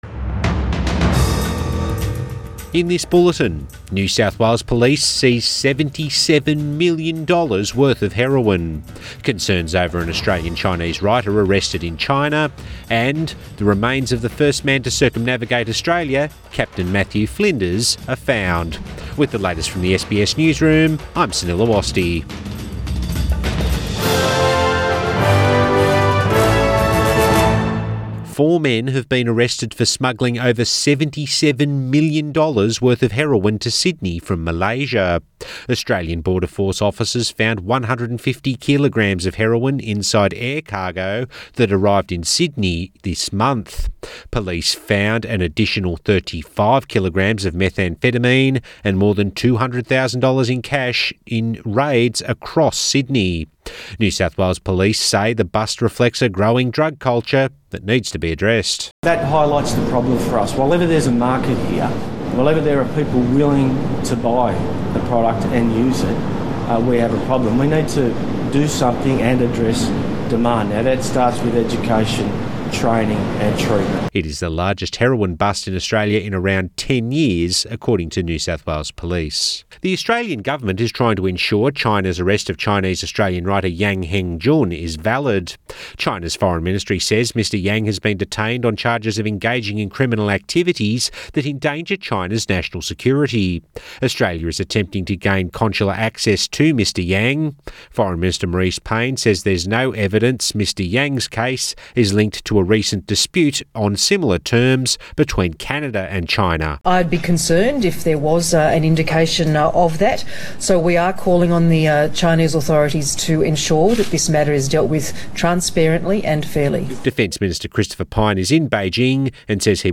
Midday Bulletin 25 January